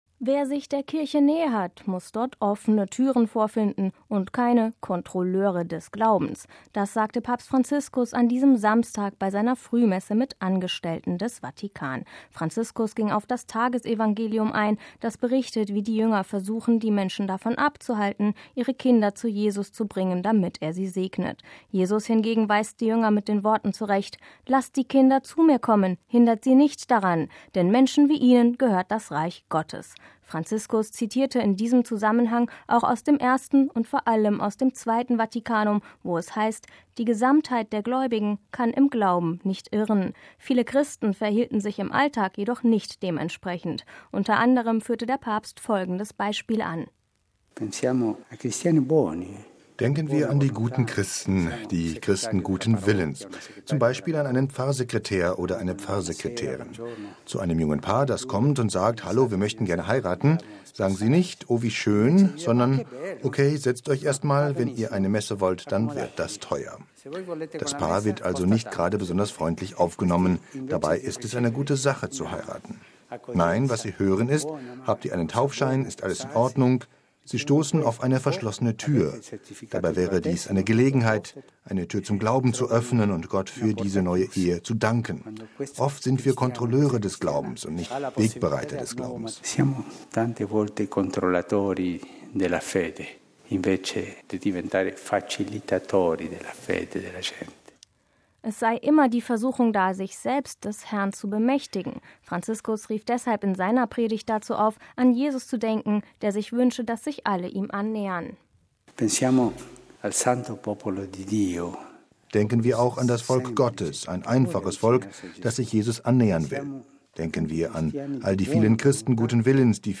MP3 Wer sich der Kirche nähert, muss dort offene Türen vorfinden und keine „Kontrolleure des Glaubens“: Das sagte Papst Franziskus an diesem Samstag bei seiner Frühmesse mit Angestellten des Vatikan.